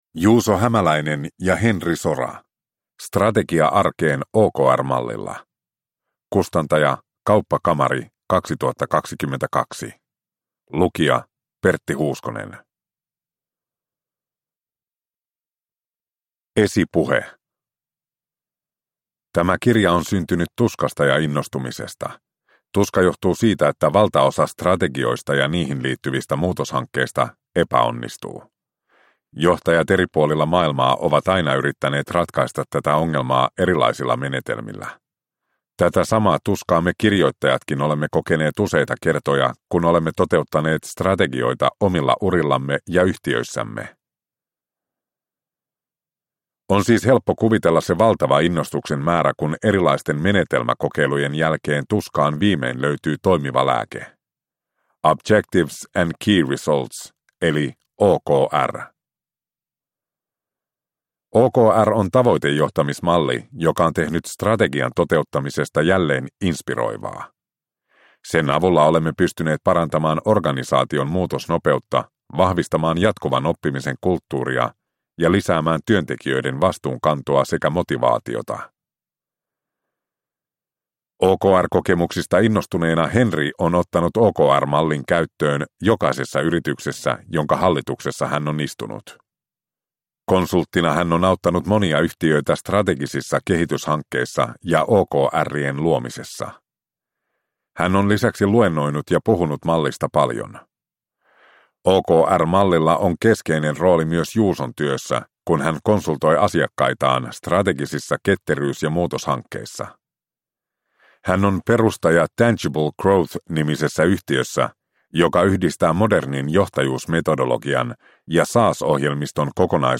Strategia arkeen OKR-mallilla – Ljudbok – Laddas ner